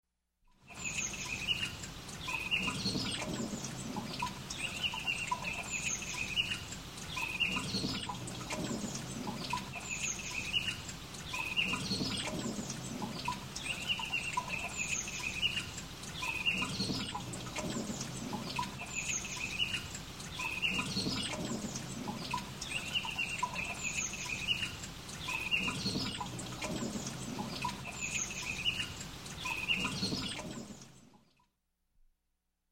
Тут вы можете прослушать онлайн и скачать бесплатно аудио запись из категории «Дождь, шум дождя».